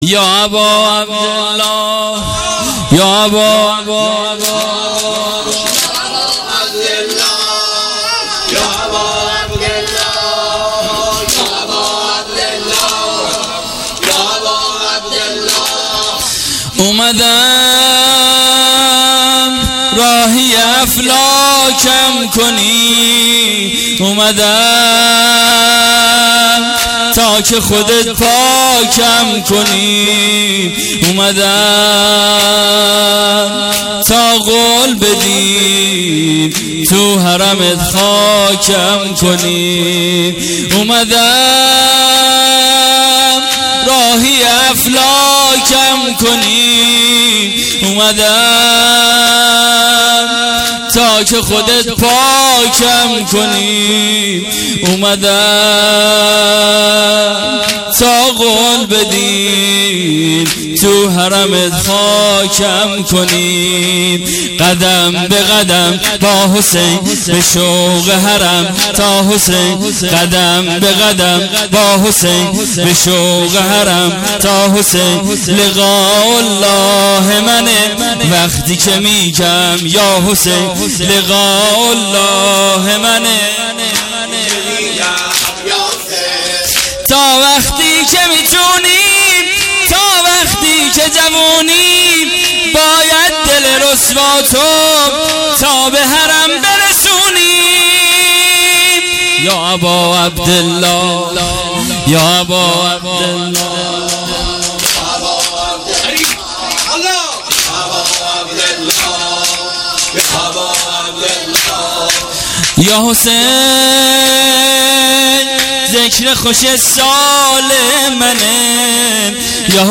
شب چهارم محرم الحرام۱۳۹۸